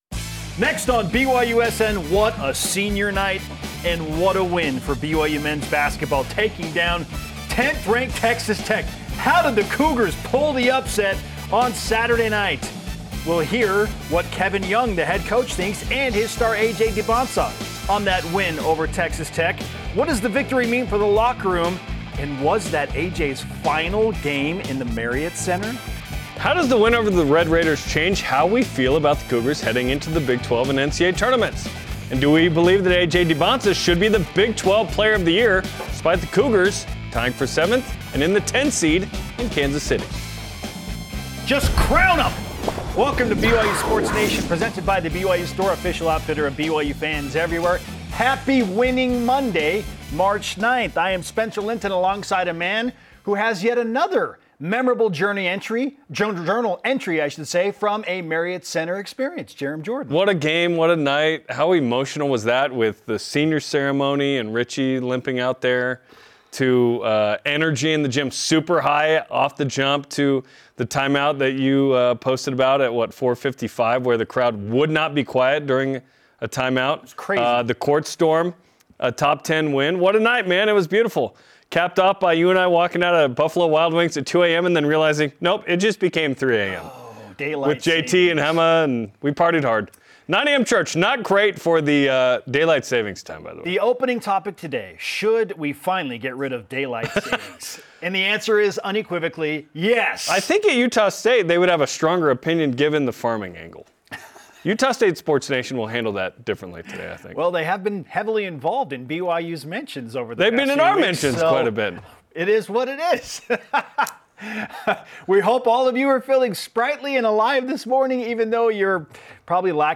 BYU wraps the regular season with a big win over Texas Tech. We recap the victory, look ahead to the Big 12 Tournament and March Madness, and feature interviews from the Marriott Center with AJ Dybantsa and BYU head coach Kevin Young.